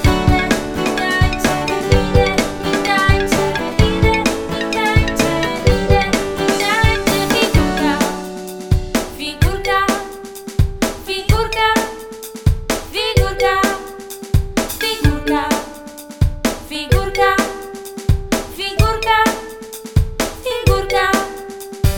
Zabawy muzyczne 0 Facebook Twitter